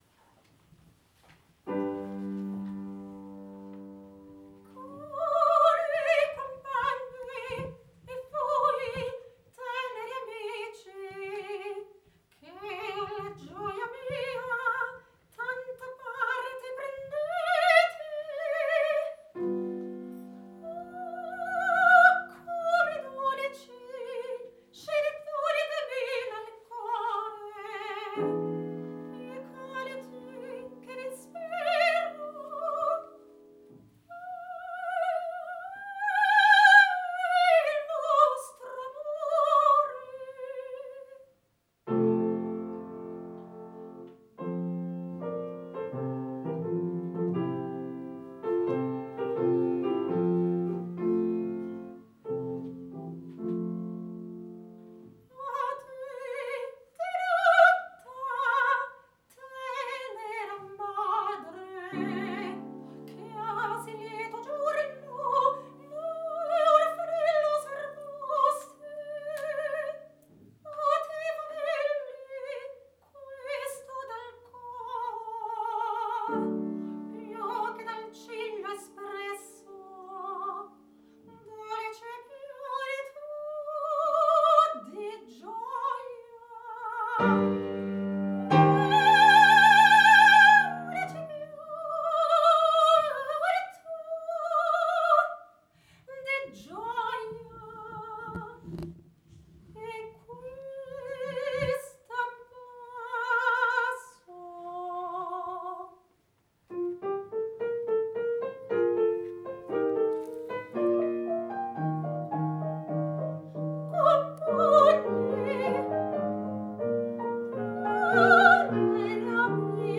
10/08/2014, Castle Colz, La Villa (BZ):
Opera arias by V.Bellini and pieces from Fanes Poem Musical
Live recordings